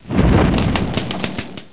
FIREBALL.mp3